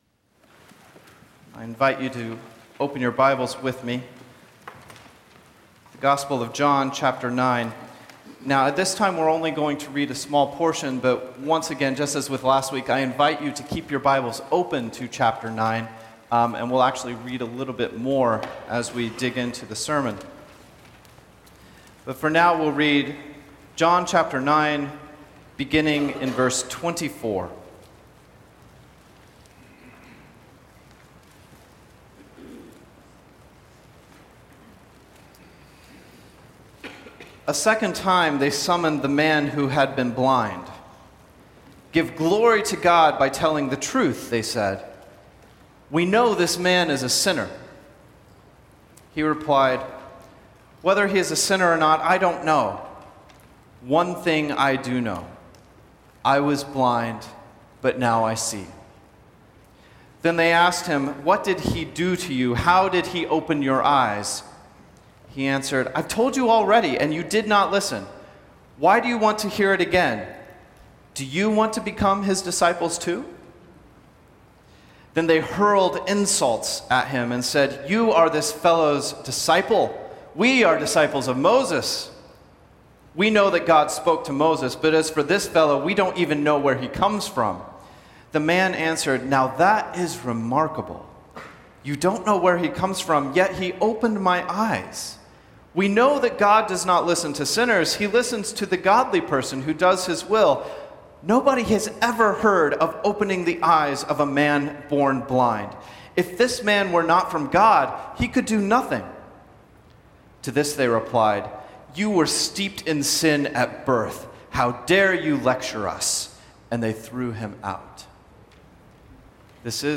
Series: Standalone Sermon
John 9:24-34 Service Type: Sunday AM Topics